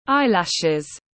Lông mi tiếng anh gọi là eyelashes, phiên âm tiếng anh đọc là /ˈaɪ.læʃ/.
Eyelashes /ˈaɪ.læʃ/
Eyelashes.mp3